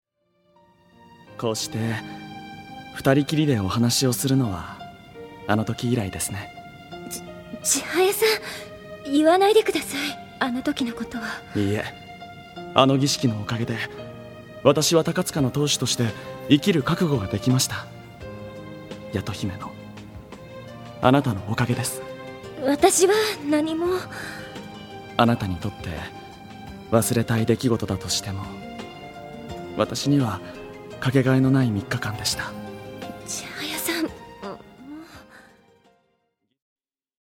本編で描かれなかった東吾＆朝霞の甘い結婚式を、豪華声優陣による魅惑のヴォイスで紡ぐ。
宍戸朝霞：柚木涼香